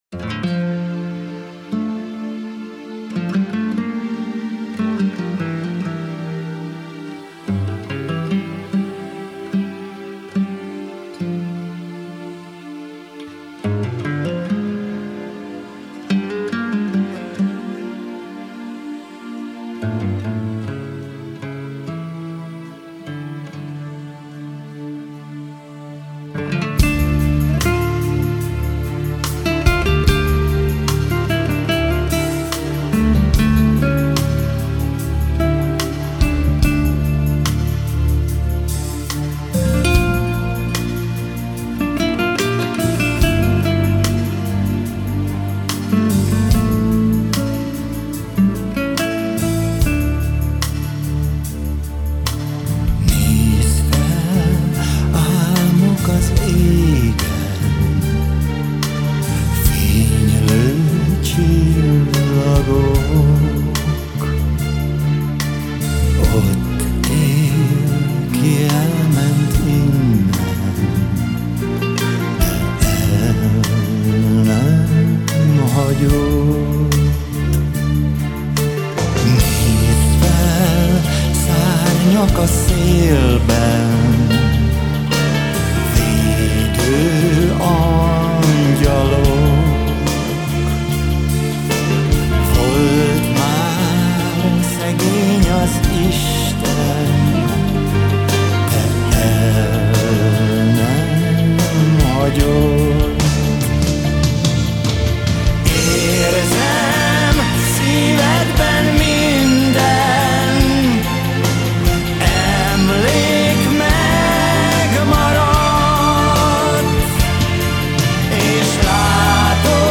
Очень грустная песня.